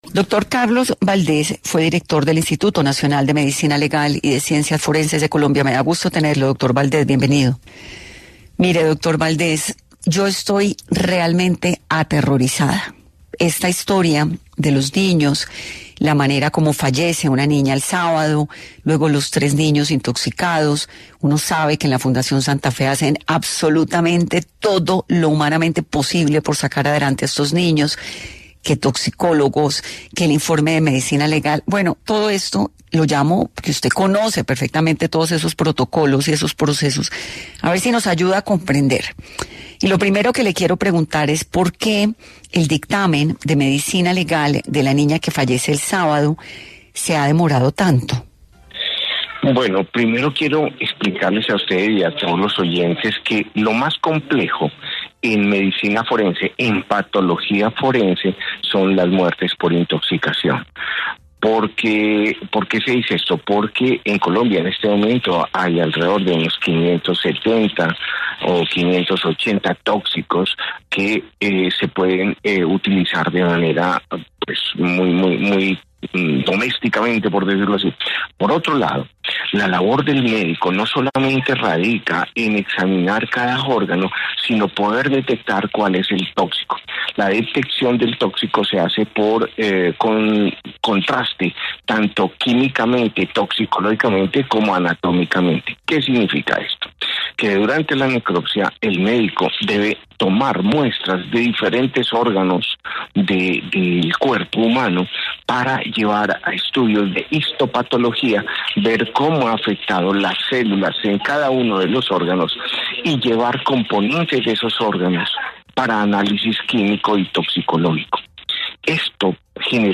En entrevista para 10AM, el Dr. Carlos Valdés, exdirector del Instituto Nacional de Medicina Legal y Ciencias Forenses, especuló sobre lo que está pasando, y dio toda una explicación sobre la intoxicación y sus características médicas.